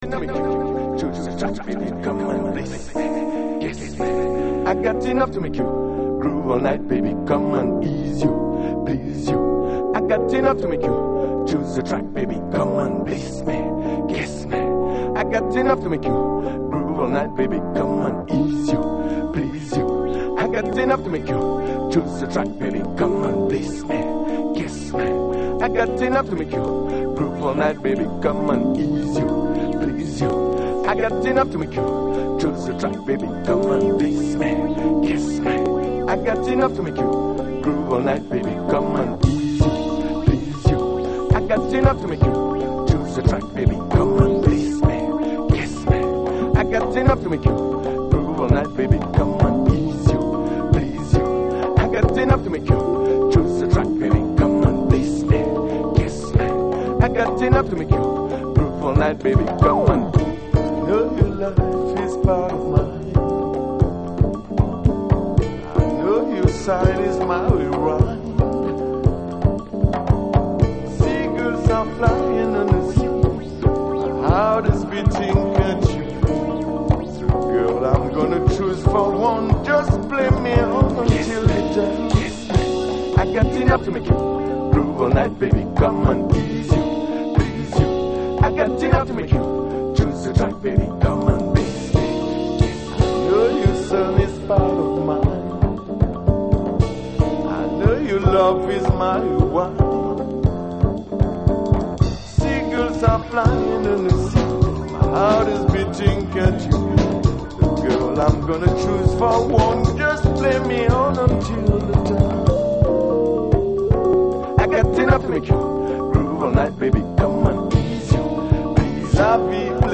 House / Techno
FORMAT - 12inch
今ではなかなか無い芳醇なアコースティックなディープハウスであります。